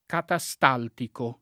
vai all'elenco alfabetico delle voci ingrandisci il carattere 100% rimpicciolisci il carattere stampa invia tramite posta elettronica codividi su Facebook catastaltico [ kata S t # ltiko ] agg. e s. m. (med.); pl. m. ‑ci